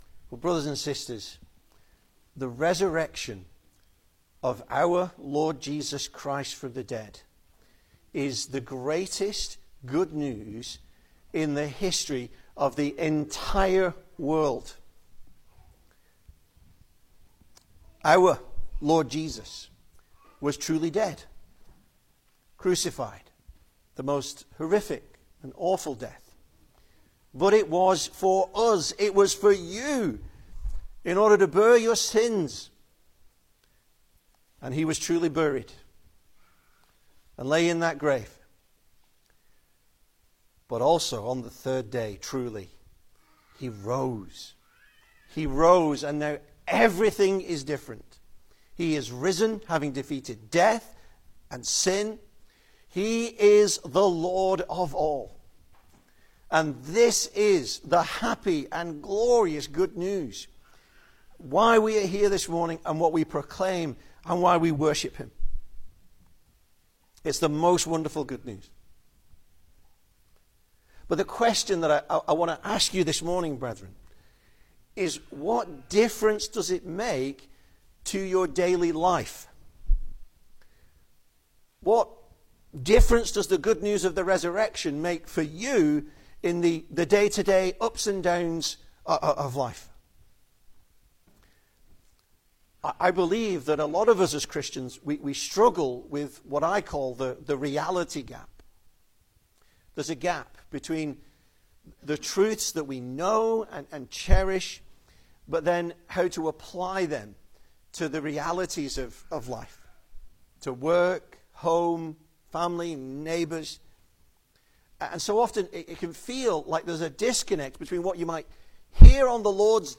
Service Type: Sunday Morning
Easter Sermons